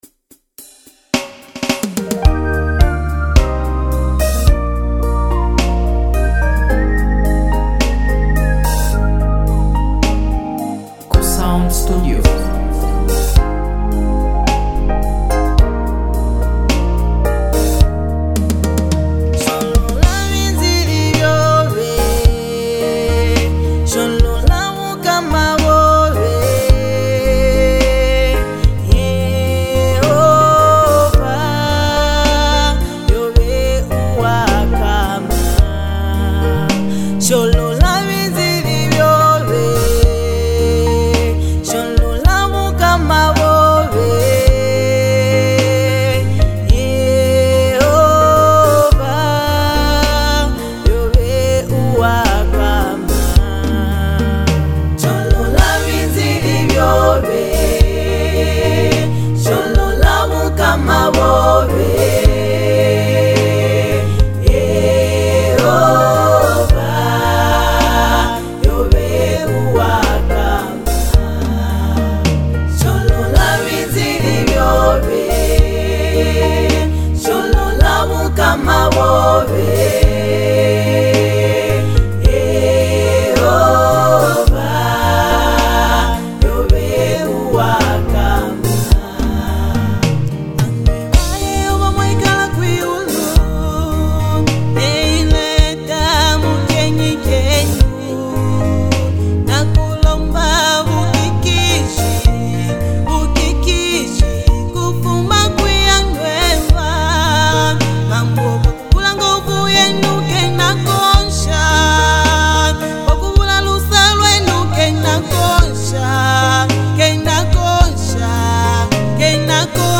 deeply spiritual and uplifting song
Through touching lyrics and a soul-lifting melody
passionate delivery
gospel song